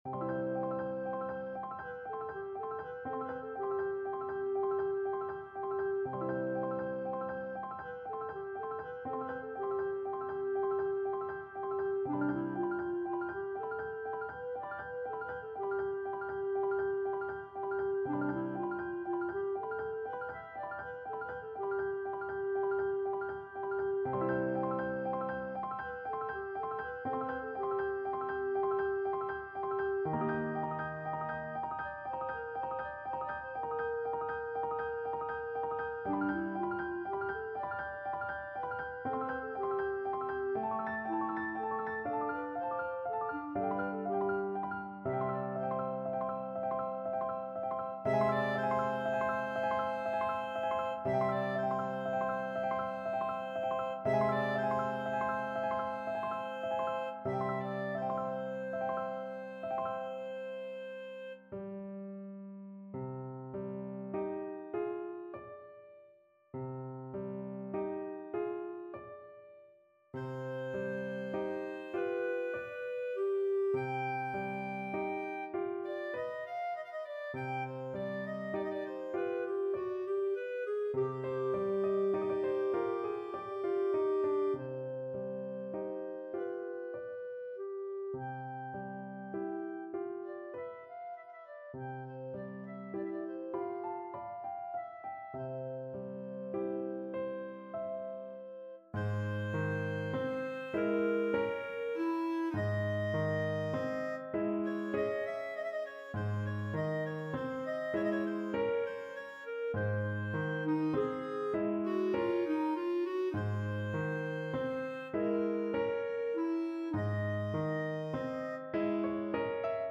Clarinet version
Allegretto =120
3/8 (View more 3/8 Music)
Classical (View more Classical Clarinet Music)